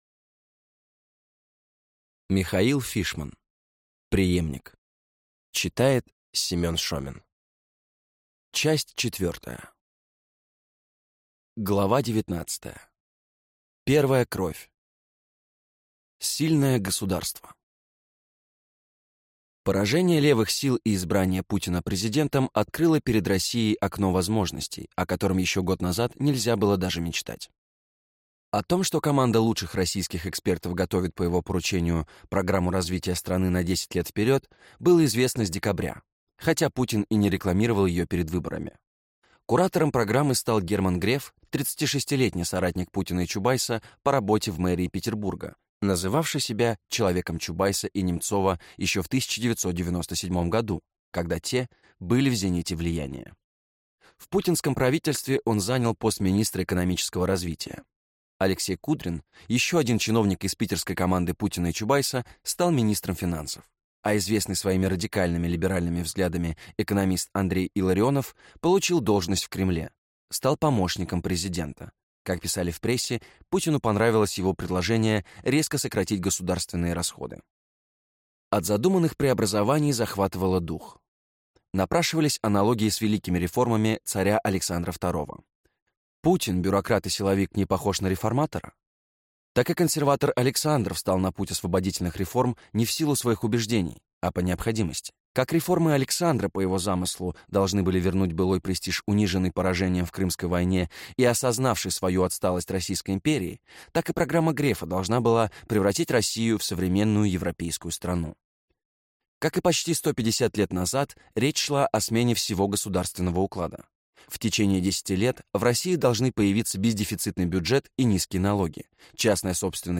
Аудиокнига Преемник (Часть 4) | Библиотека аудиокниг